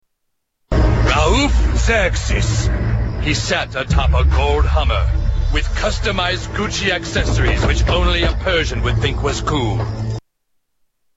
Narrator describes Xerxes
Category: Television   Right: Personal